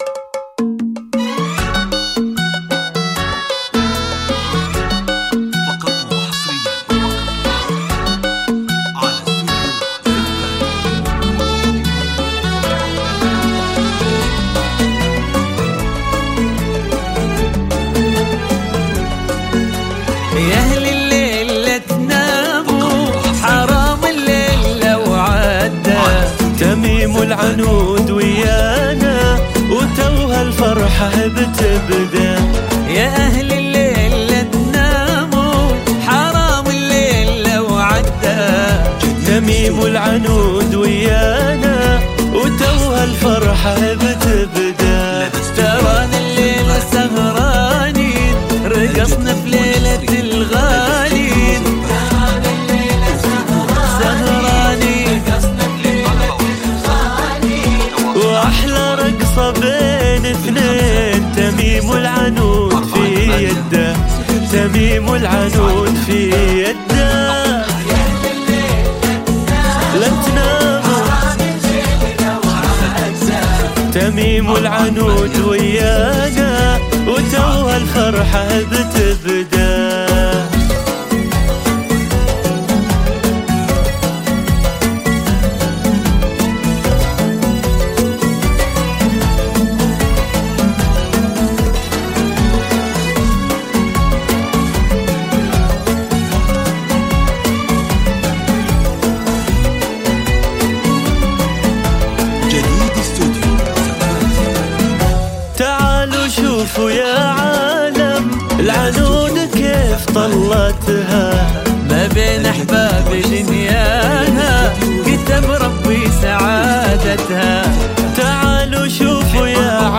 زفة كوشة – تنفيذ بالاسماء